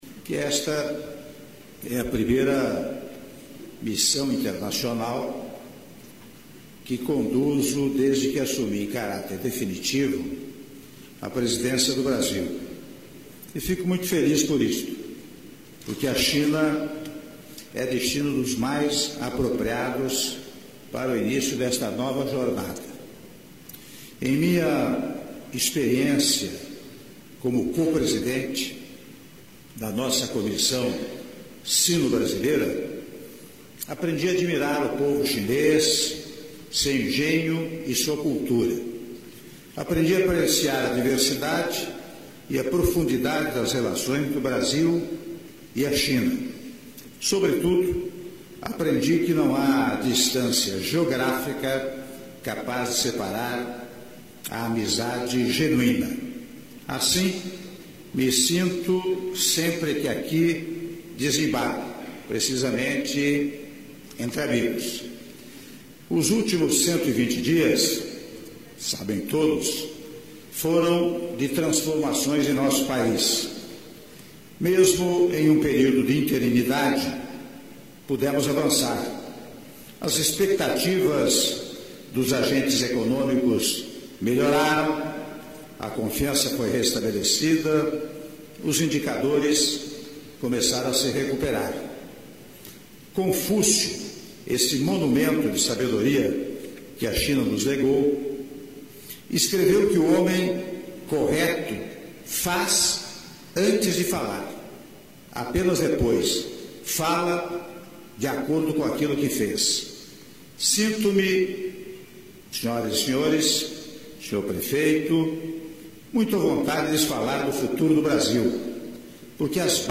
Áudio do discurso do Senhor Presidente da República, Michel Temer, na cerimônia de encerramento do Seminário Empresarial de Alto Nível Brasil-China - Xangai/China-(08min04s)